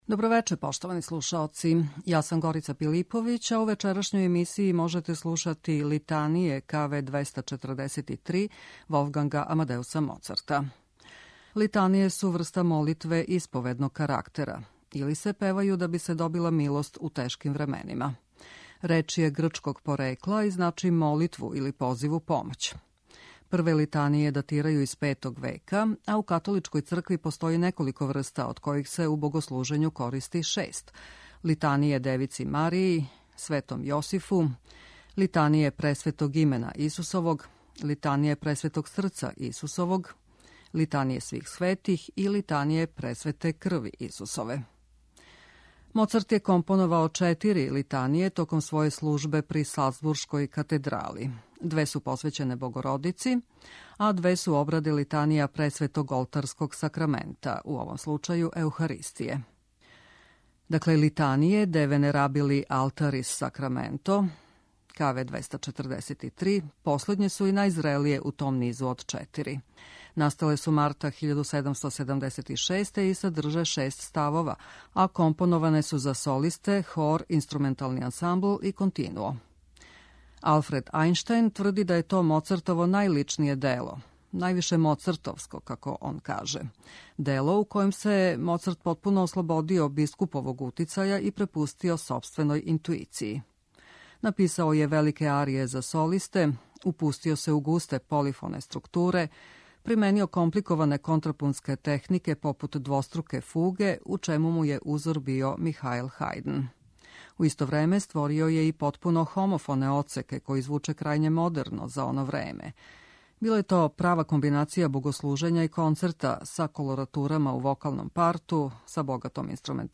Настале су марта 1776. и садрже шест ставова, а компоноване су за солисте, хор, инструментални ансамбл и континуо.